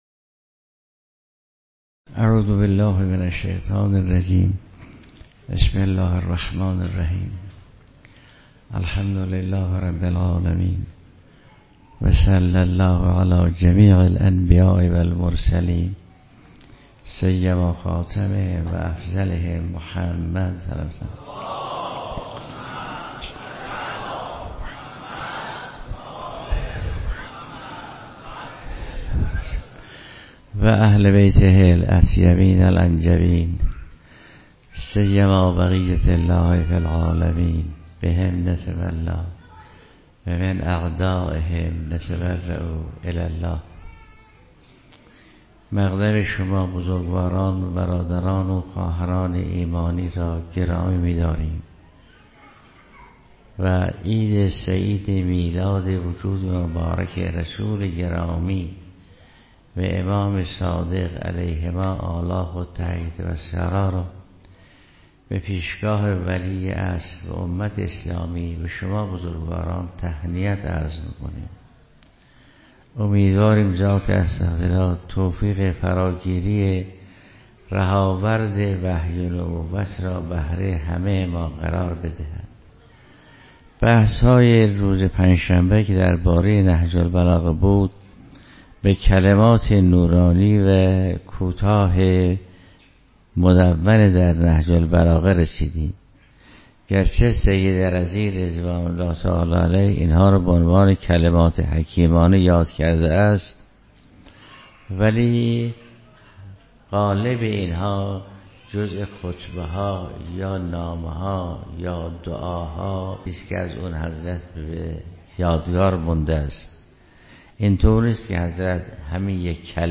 درس اخلاق ایت الله جوادی آملی